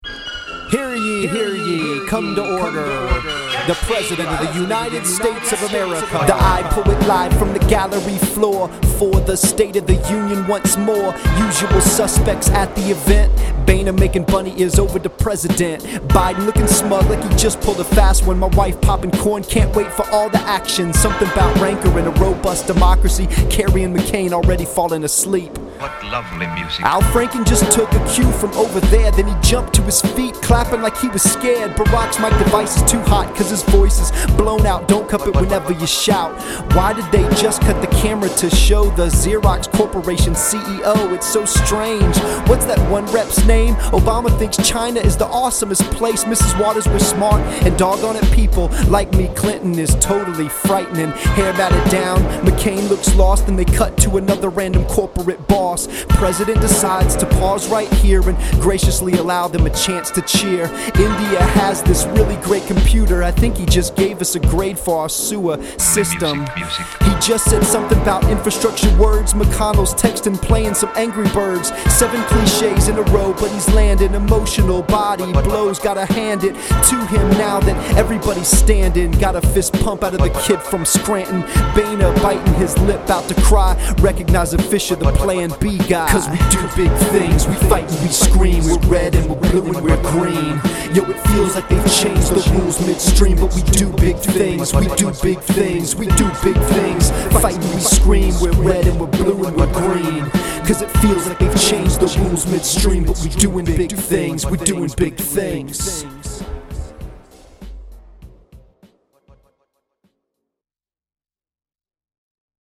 To rap the news stories that make the average man’s nose bleed.